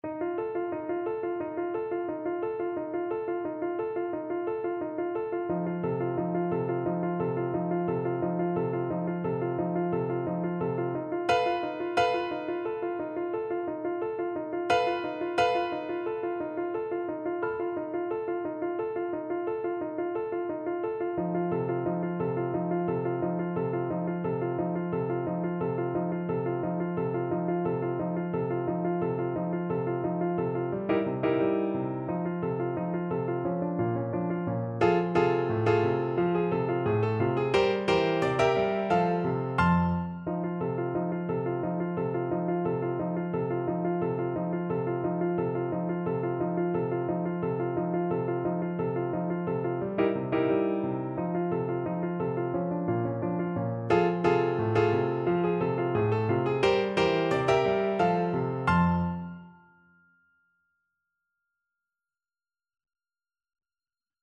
Flute
F major (Sounding Pitch) (View more F major Music for Flute )
2/2 (View more 2/2 Music)
Relentlessly forward! =c.88
Traditional (View more Traditional Flute Music)